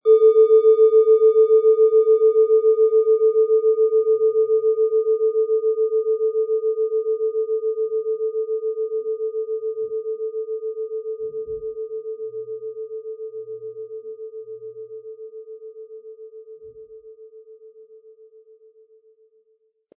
Wie klingt diese tibetische Klangschale mit dem Planetenton Biorhythmus Seele?
Aber uns würde der kraftvolle Klang und diese außerordentliche Klangschwingung der überlieferten Fertigung fehlen.
Ein schöner Klöppel liegt gratis bei, er lässt die Klangschale harmonisch und angenehm ertönen.
MaterialBronze